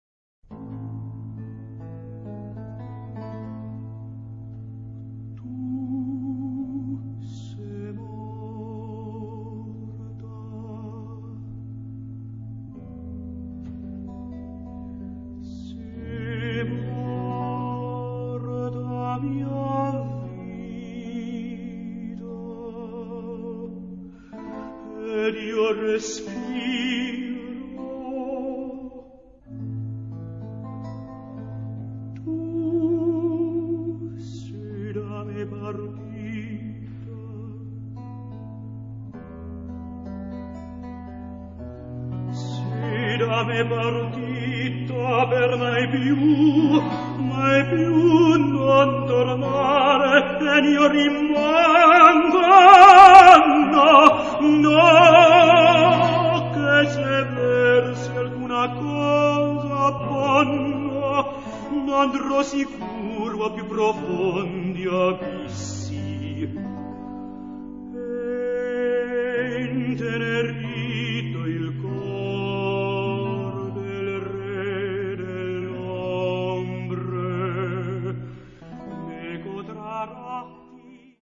Beluister zijn bezwering.'